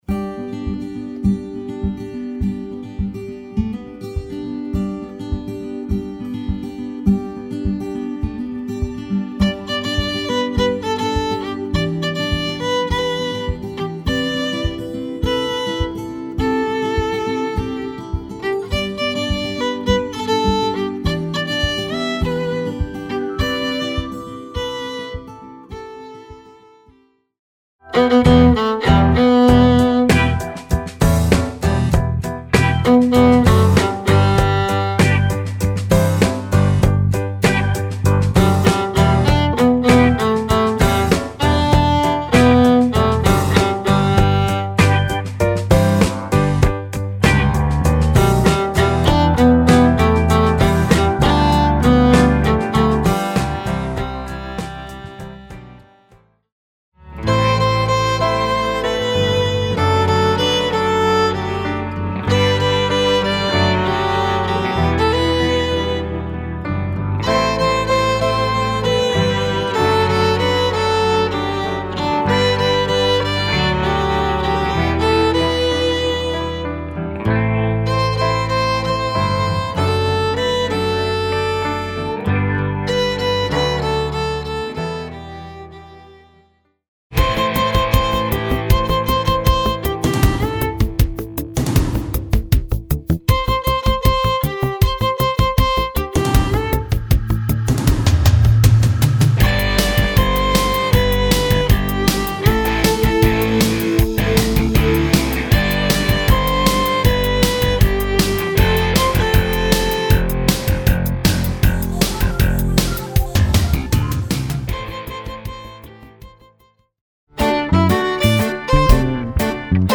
Voicing: Viola